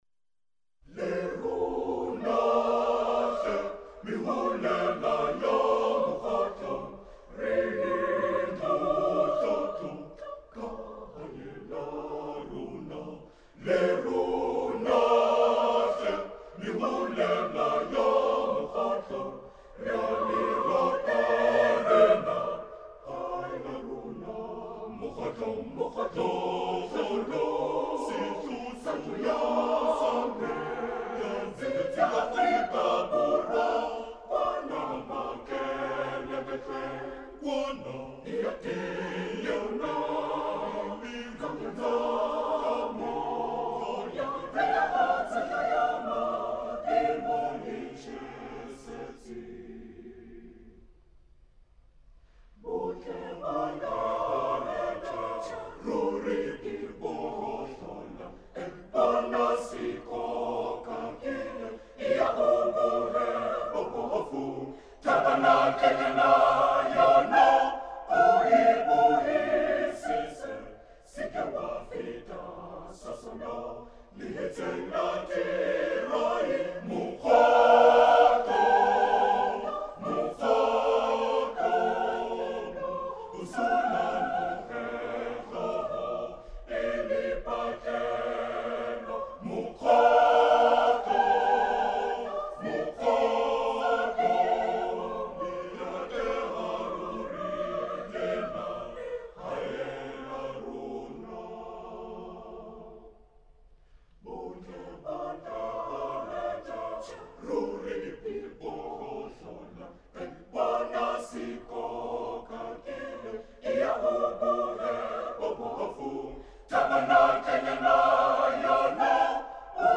choir SATB
Traditional style | traditional life